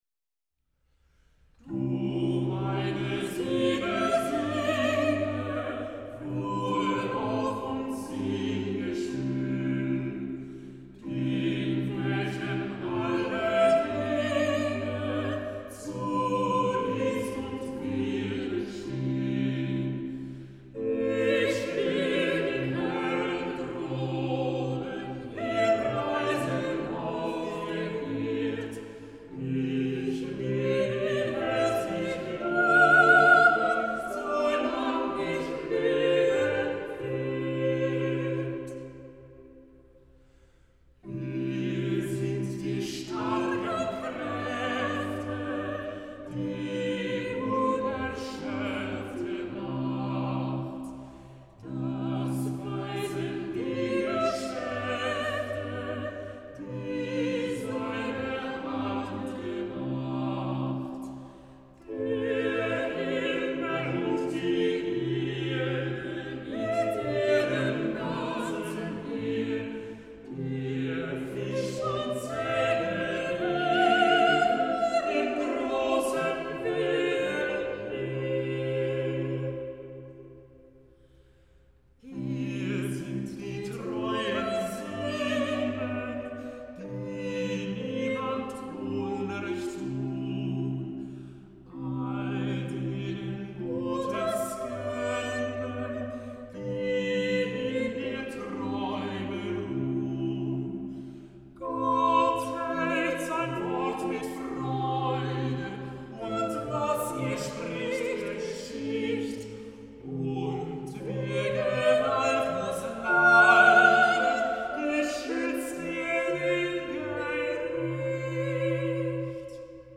Text: Paul Gerhardt 1653 Melodie: Johann Georg Ebeling 1666